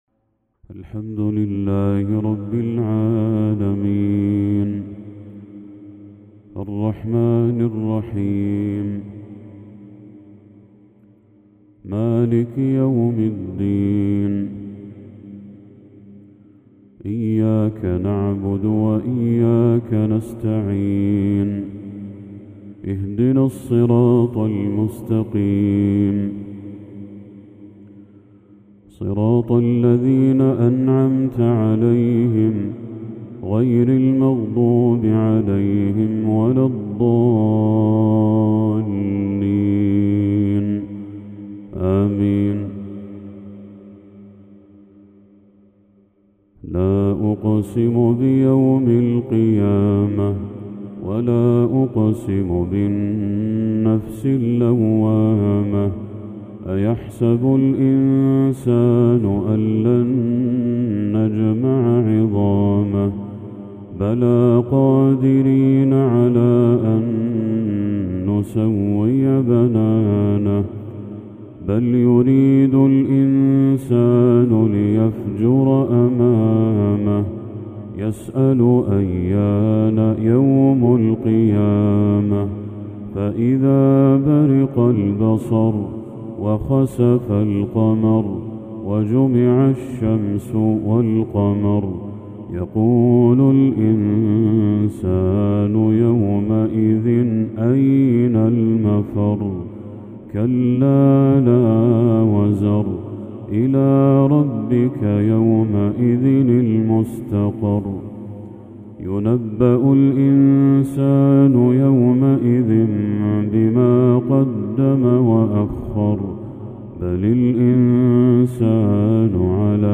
تلاوة شجيَّة من سورة القيامة للشيخ بدر التركي | عشاء 29 ذو الحجة 1445هـ > 1445هـ > تلاوات الشيخ بدر التركي > المزيد - تلاوات الحرمين